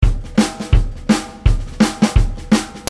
SAMPLES DE BATERIA
¿Te gustan los samplers de ritmos y redobles de batería?, aquí tengo unos cuantos bajados de Internet a tu disposición en formato mp3.